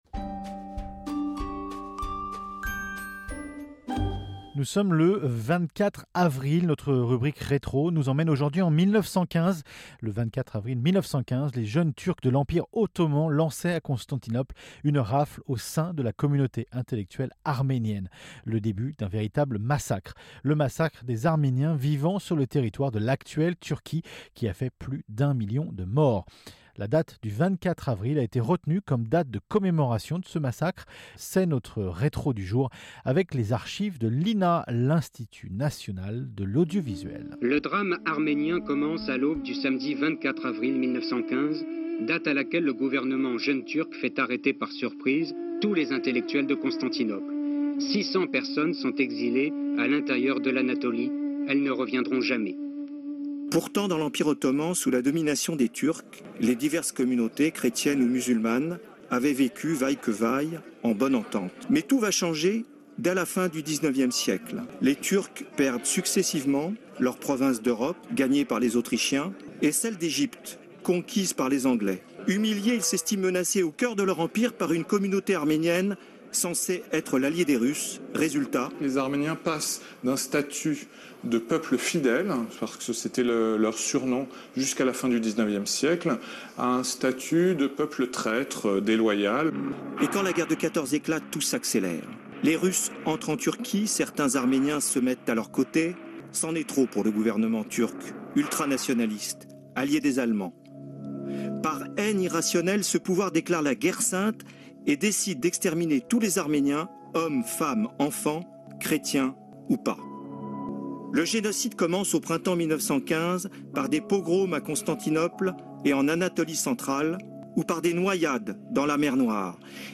La date du 24 avril a été retenue comme date de commémoration de ce massacre.... c'est notre rétro du jour avec les archives de l'INA..